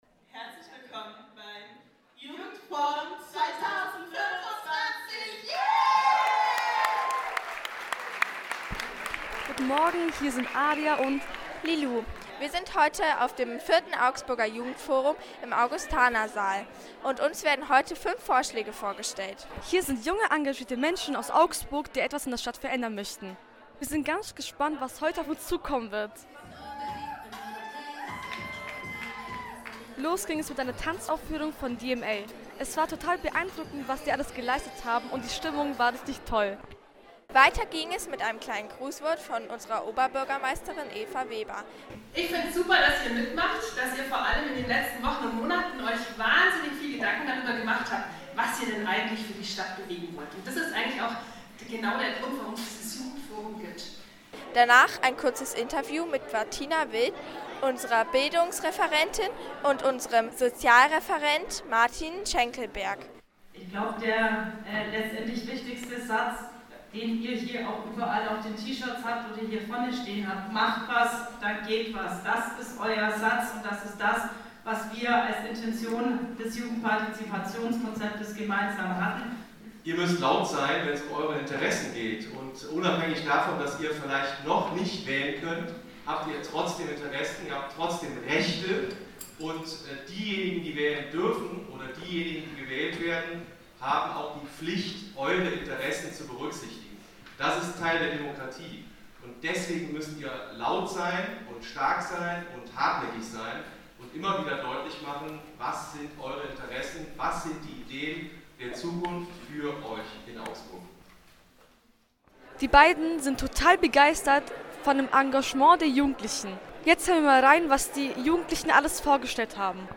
Reportage über das Jugendforum 2025
Reportage-Jugendforum-2025.mp3